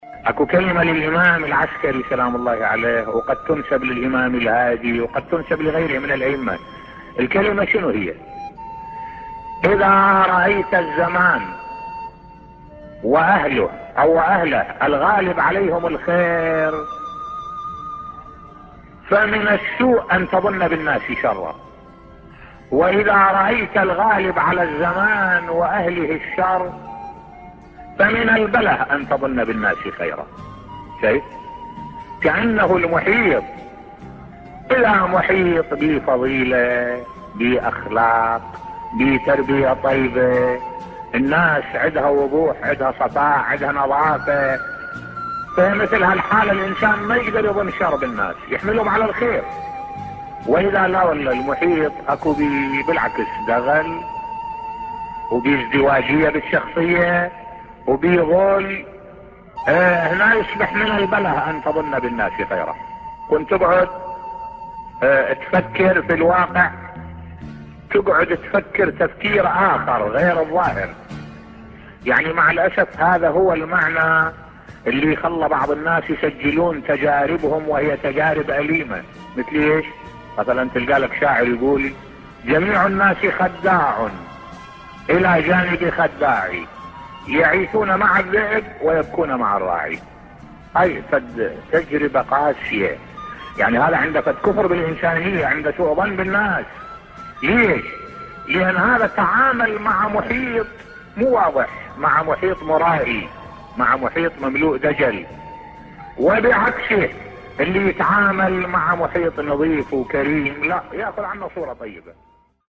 ملف صوتی إضاءات حول الظن و أبعادهِ بصوت الشيخ الدكتور أحمد الوائلي